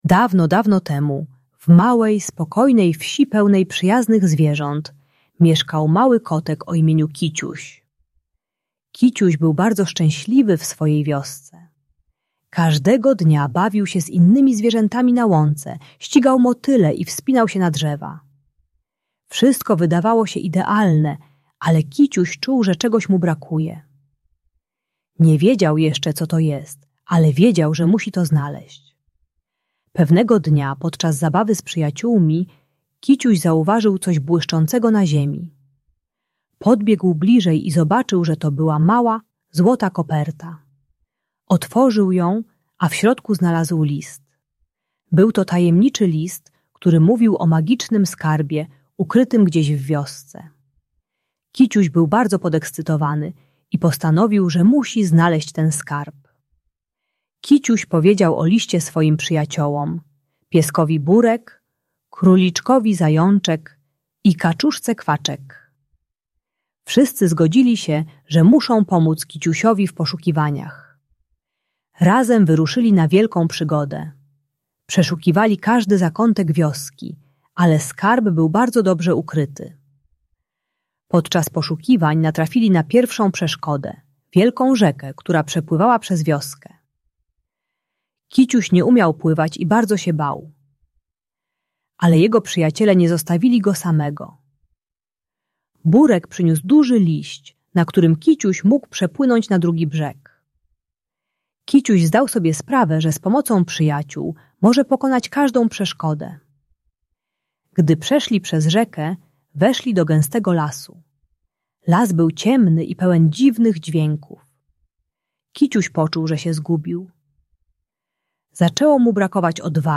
Bajka usypiająca dla dzieci 3-5 lat, która pomaga maluchowi spokojnie zasnąć. Ta bajka pomagająca zasnąć dla przedszkolaka opowiada o przygodzie kotka Kiciusia i uczy, że z przyjaciółmi można pokonać każdy strach. Spokojna narracja i pozytywne zakończenie wprowadzają dziecko w sen.